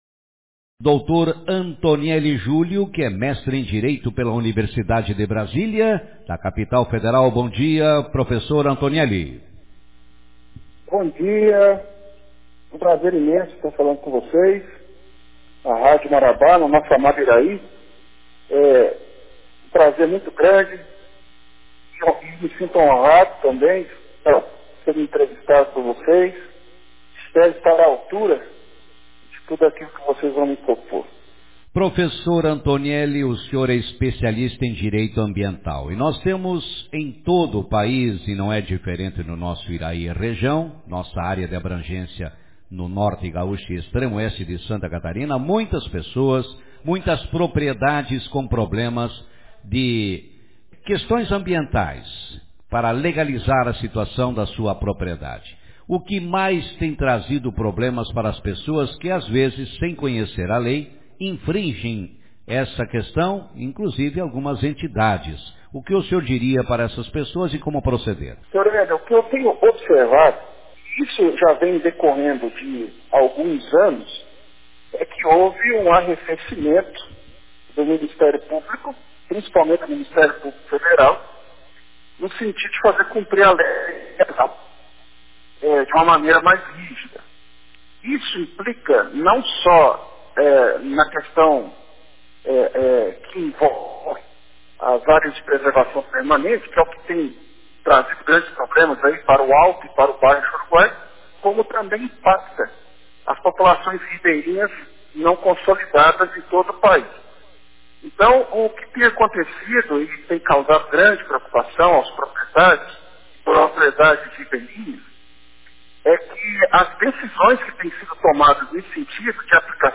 Advogado especialista fala sobre questões envolvendo o Direito Ambiental